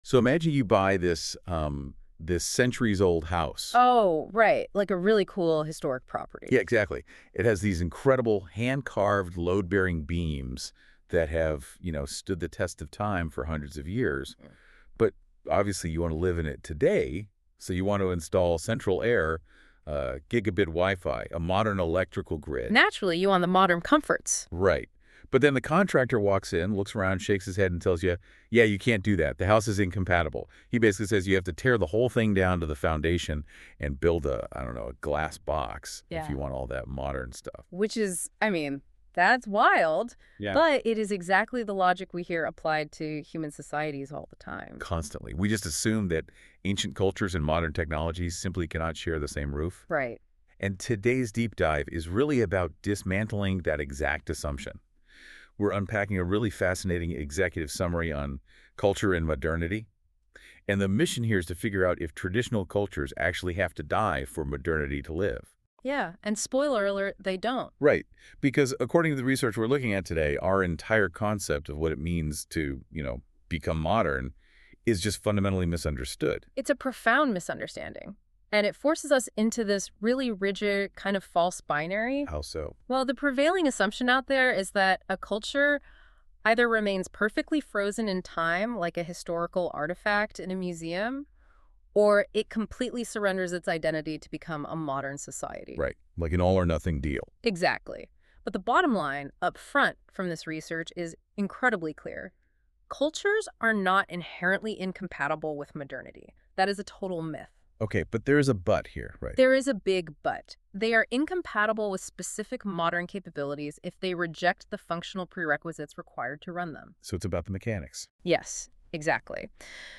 An audio discussion on the themes in this essay.